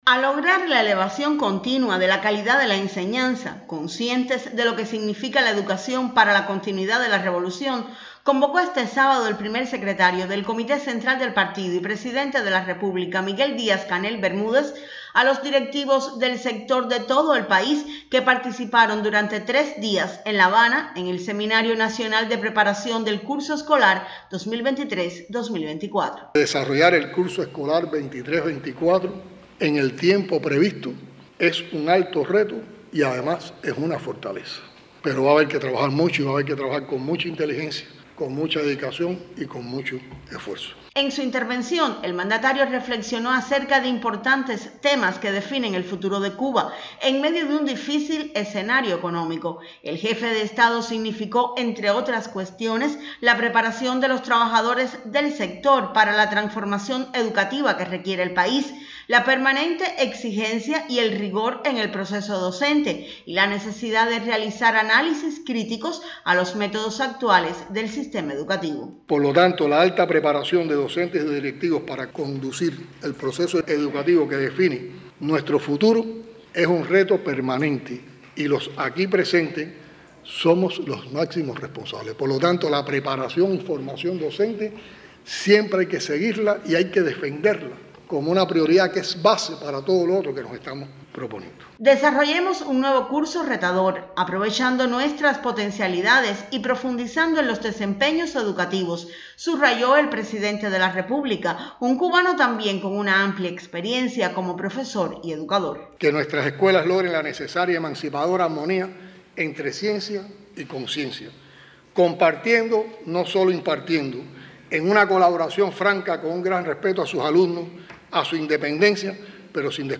Primer Secretario del Comité Central del Partido y Presidente de la República comparte reflexiones sobre el perfeccionamiento de la educación, en Seminario de Preparación del Curso Escolar 2023-2024.